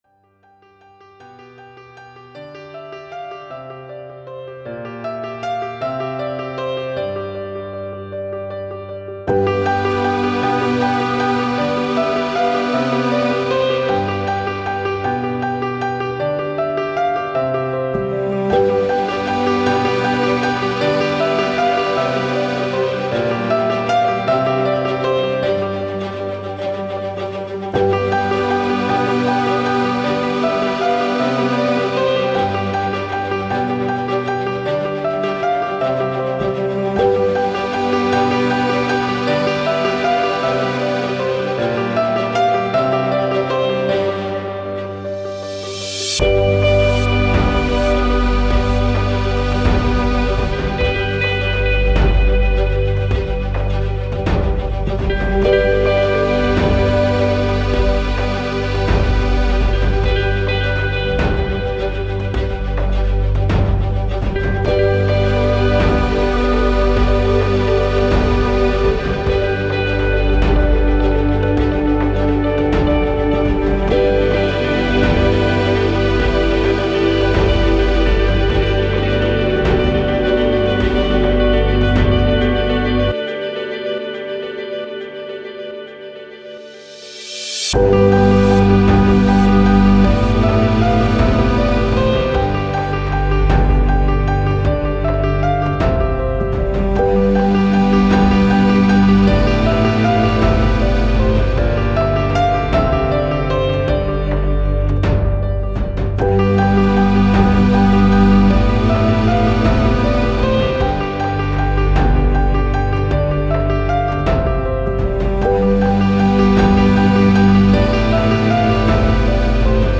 Soundtrack, Classical, Ambient, Emotive, Story, Endings